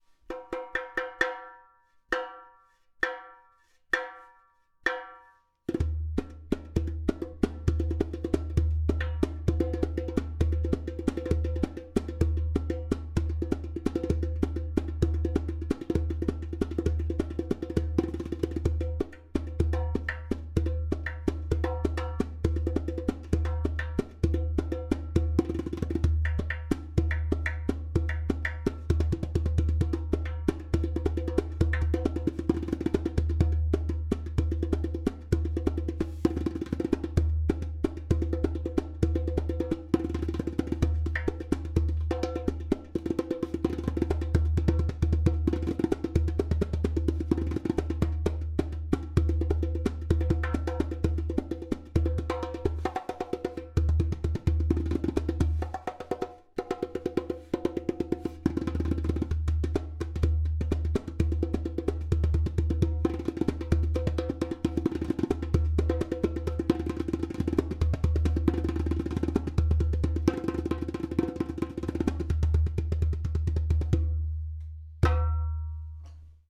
130bpm
• Strong and easy to produce clay kik (click) sound
• Even tonality around edges.
• Beautiful harmonic overtones.